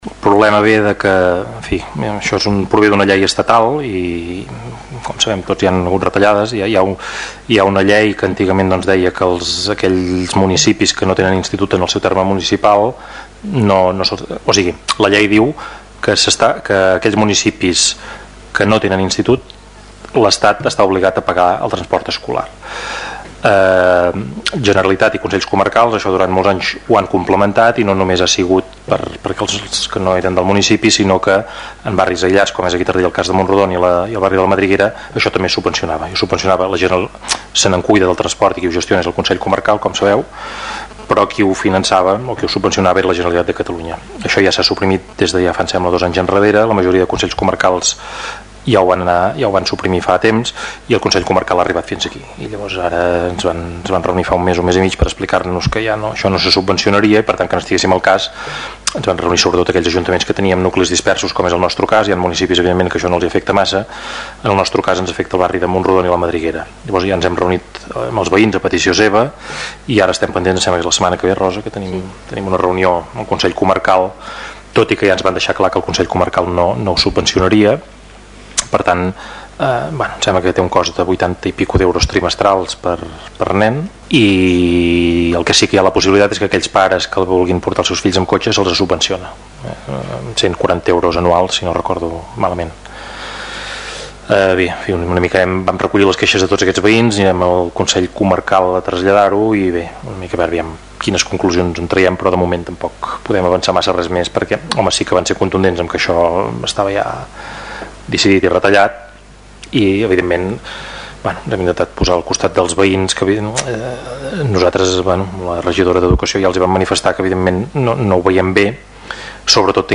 Lluís Verdaguer, en el ple de dijous, va comentar que “arran d'aquesta llei, la Generalitat i el Consell Comarcal es feien càrrec dels alumnes que vivien en nuclis aïllats” i que a Taradell afectava a la Madriguera i a Mont-rodon.